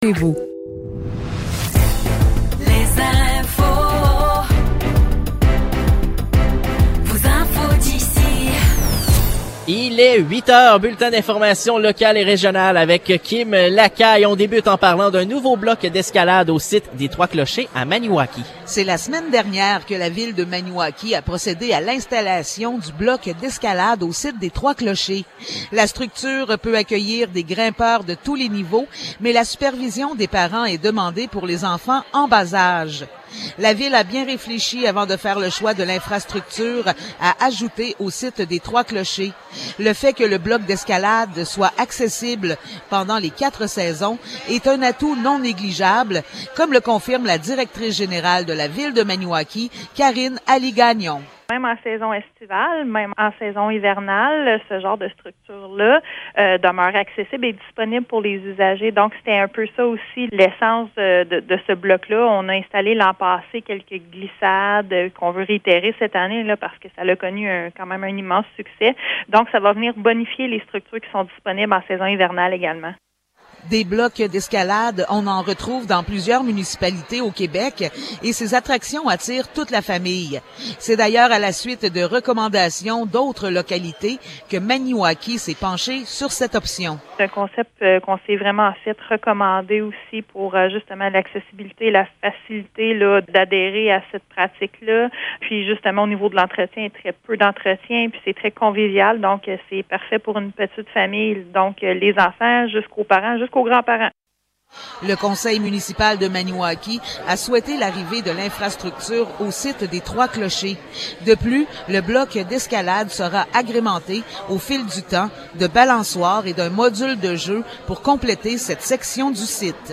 Nouvelles locales - 21 septembre 2023 - 8 h